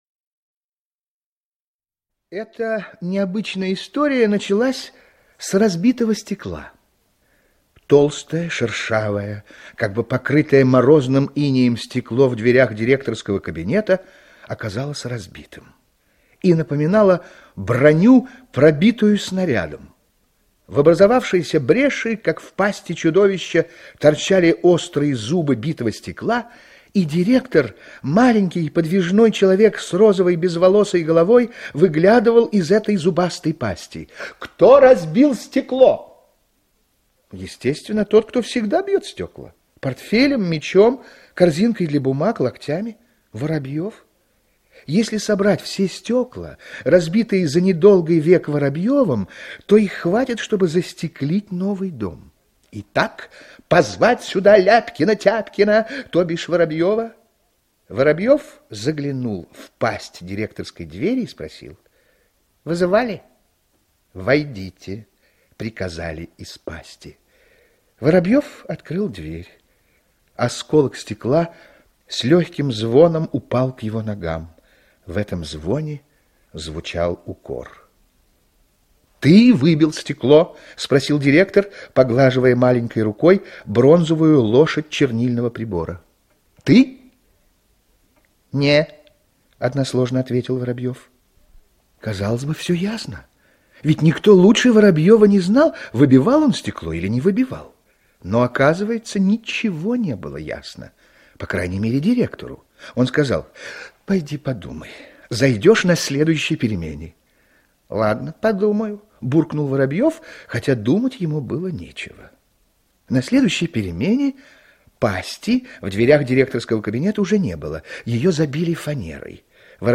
А Воробьев стекло не выбивал - аудио рассказ Яковлева Ю.Я. Однажды в двери кабинета директора школы кто-то разбил стекло...